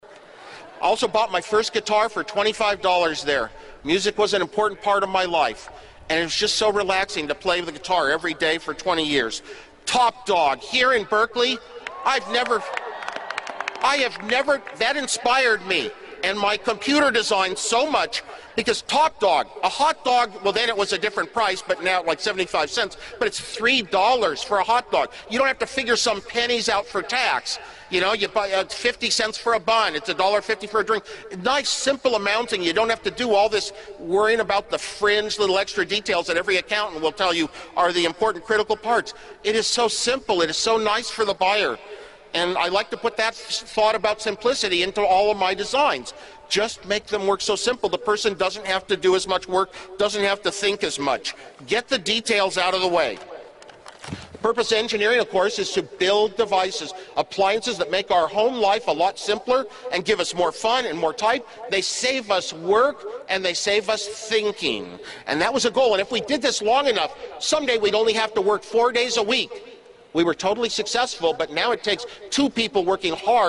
公众人物毕业演讲 第149期:史蒂夫·沃兹尼亚克于加州大学伯克利分校(6) 听力文件下载—在线英语听力室